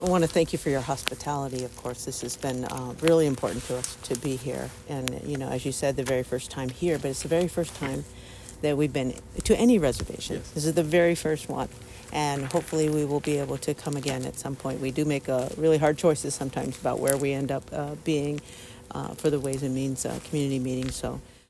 On Friday, the Oregon Legislature’s Joint Ways & Means Committee held public testimony at the old Warm Springs Elementary Gym to allow citizens the opportunity to weigh in on their support for specific bills.
Representative Tawna Sanchez is the co-chair of the joint committee: